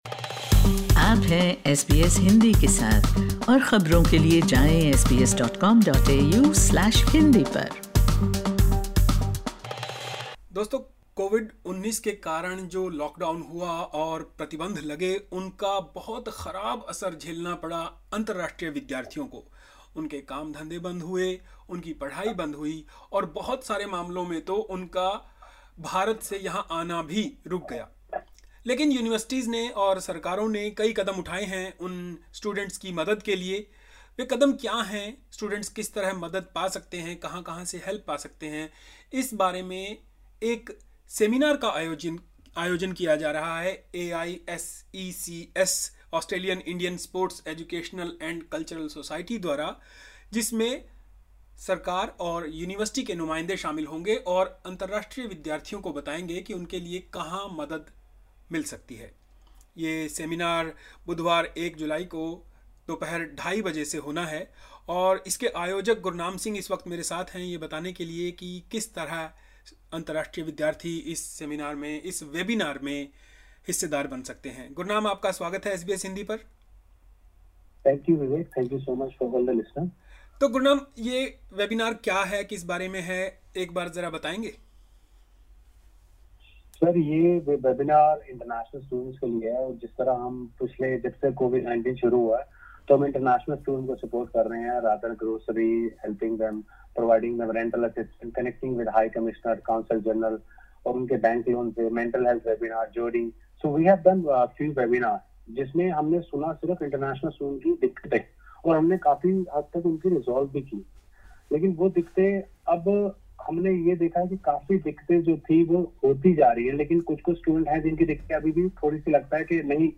Listen to this interview to find out more about the program: LISTEN TO Time to ask how universities are helping international students SBS Hindi 05:52 Hindi The webinar is organised by the Australian Indian Sports, Educational and Cultural Society (AISECS).